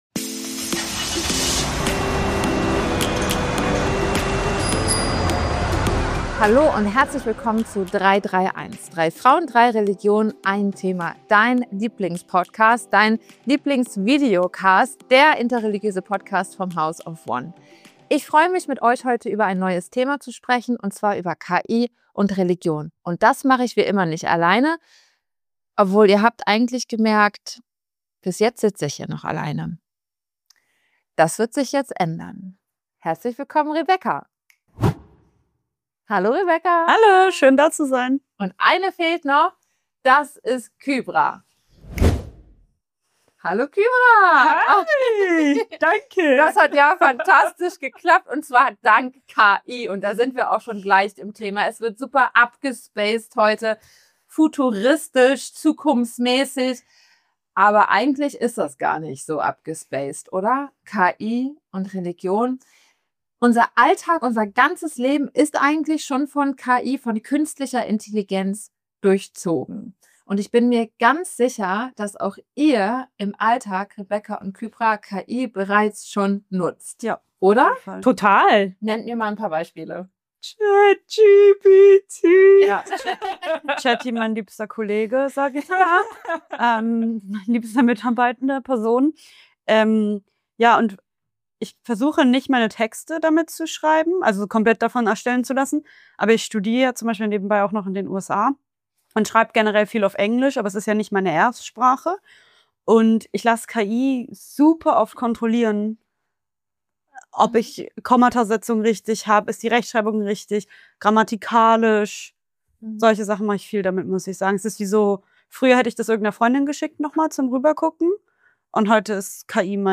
#105: Künstliche Intelligenz und Religion - Passt das zusammen? ~ 331 - 3 Frauen, 3 Religionen, 1 Thema Podcast